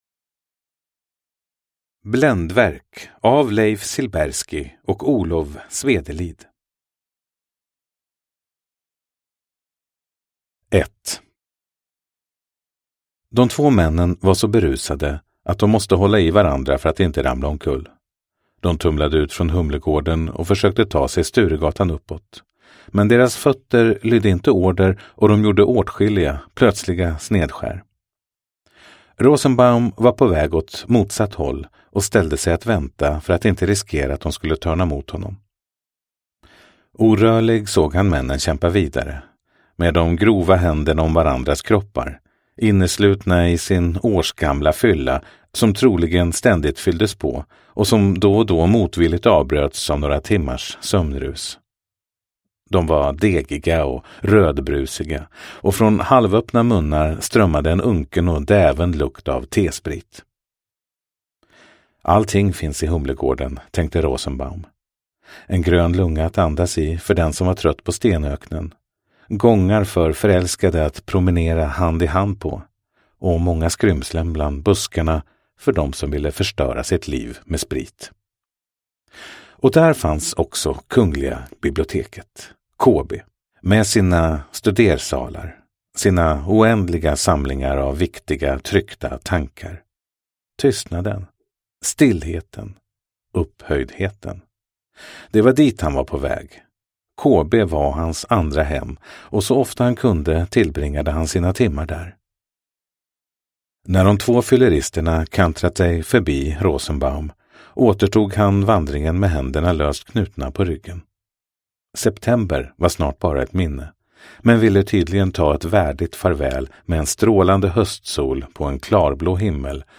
Bländverk – Ljudbok – Laddas ner